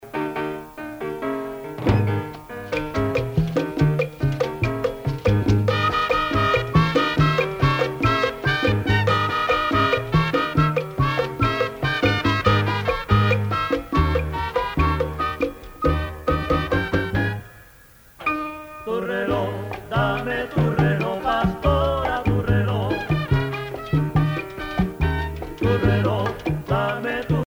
Usage d'après l'analyste gestuel : danse
Pièce musicale éditée